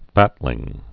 (fătlĭng)